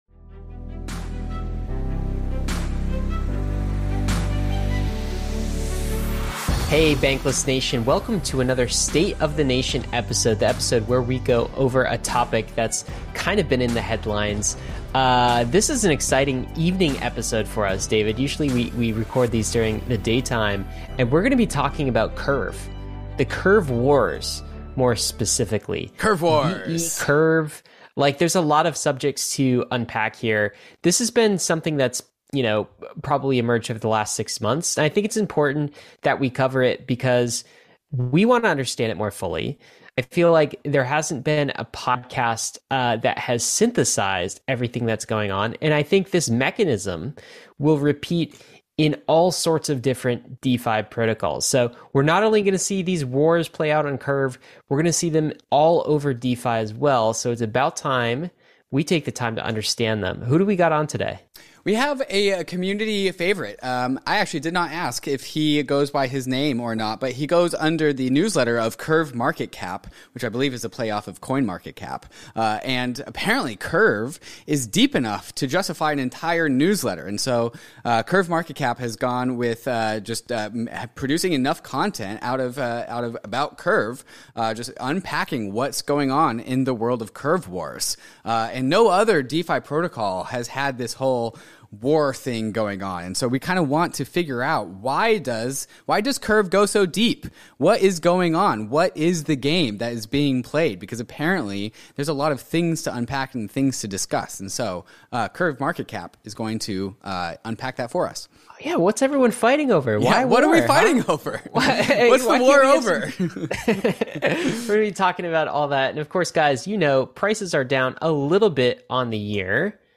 The conversation dives deep into tokenomics, exploring the role of bribing in incentives and how Convex Finance enhances user engagement.